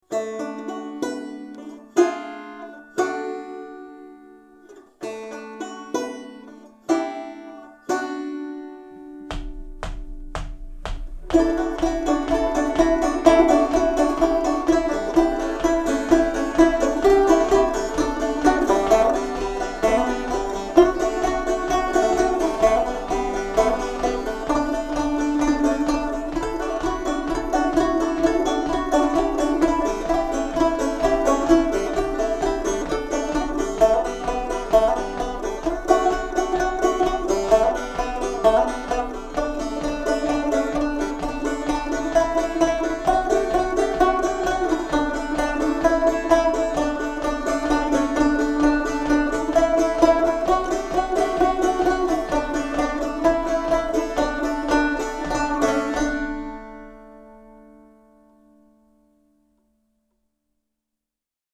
Pour finir , mon vieux banjo =>
Pour le banjo, c'est pas facile de prendre ça.J'étais loin d'une part, et ce n'est pas un modèle